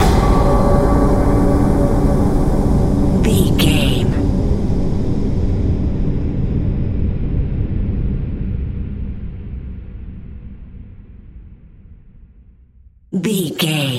Sound Effects
Atonal
ominous
haunting
eerie
horror
Horror Pads
Horror Synths
Horror Ambience